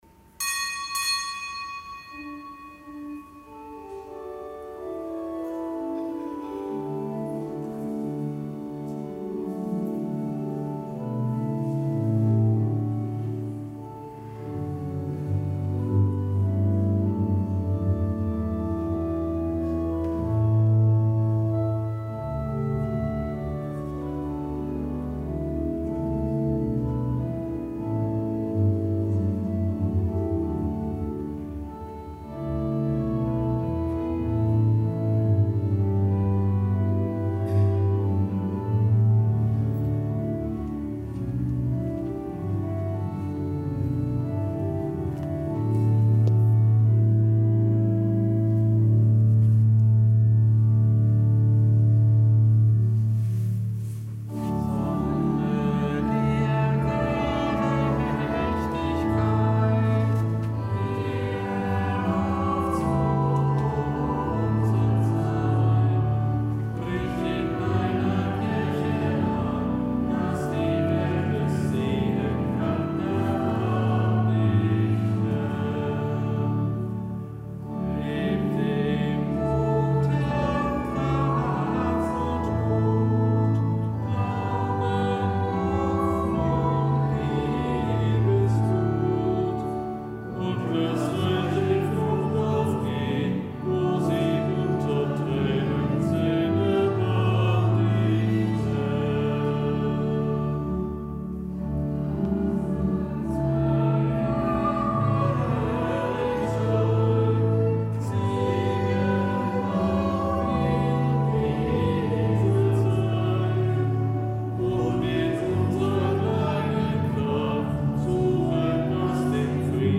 Kapitelsmesse aus dem Kölner Dom am Samstag der zweiundzwanzigsten Woche im Jahreskreis. Zelebrant: Weihbischof Rolf Steinhäuser.